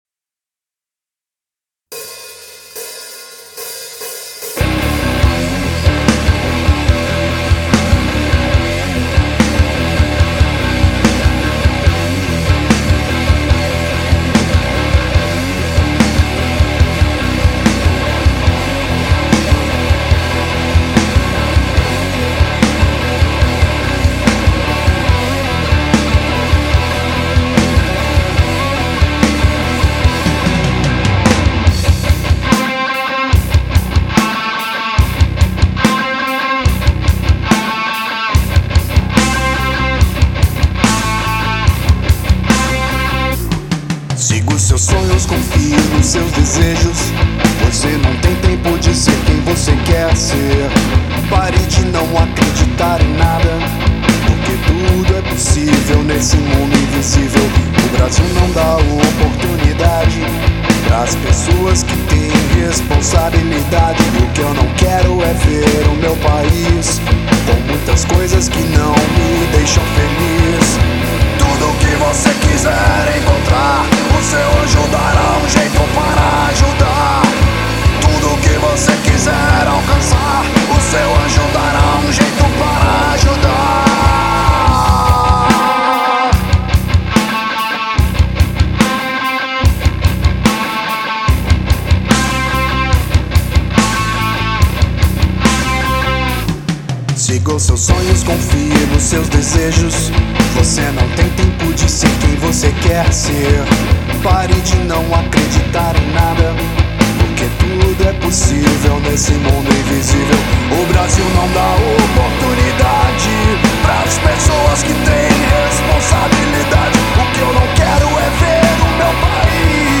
EstiloRock